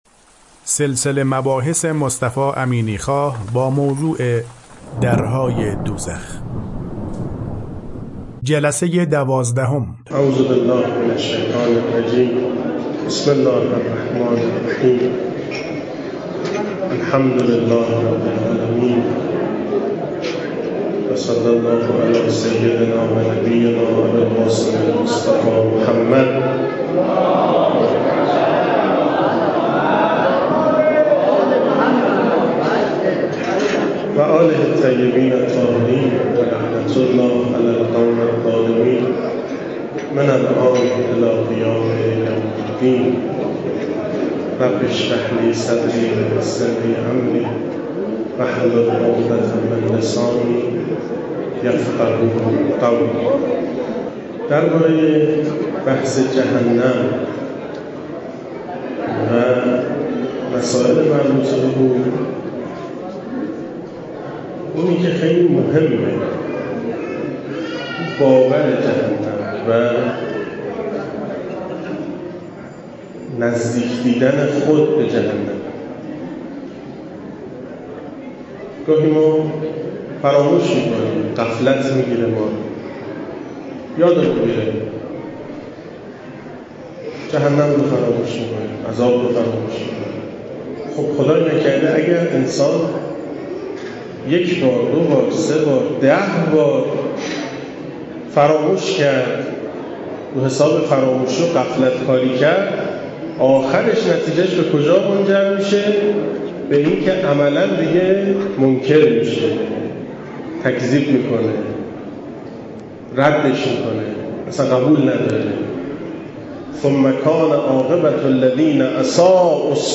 سخنرانی با موضوع در های دوزخ ،در ایام ماه مبارک رمضان سال 1396،رمضان1438هجری قمری،جهان آخرت/دوزخ و جهنم،معارف الهی